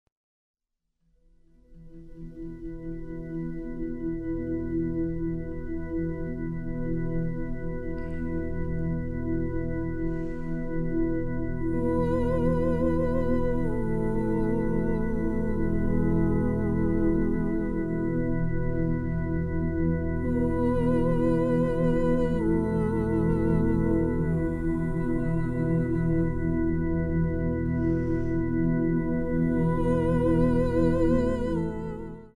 with singing and instrumental music.
marimba, percussion
piano, synthesizer, amadinda